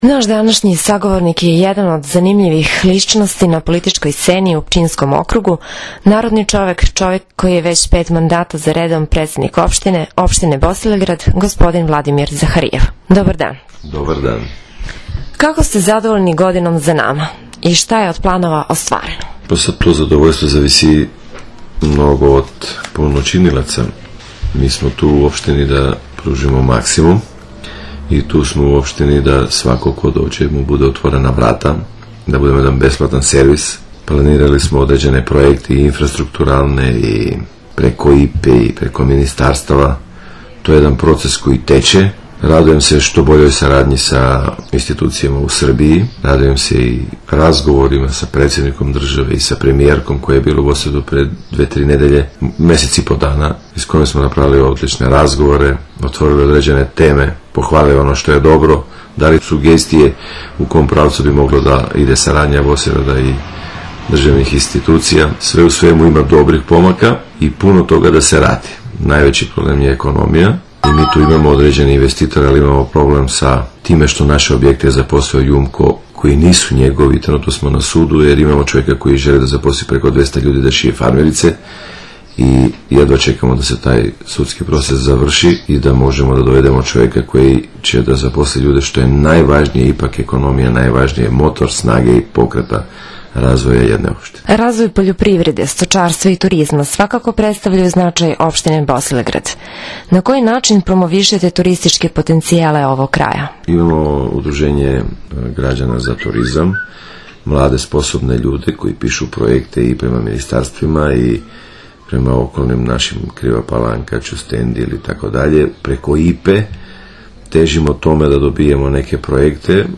Razgovor sa predsednikom opštine Bosilegrad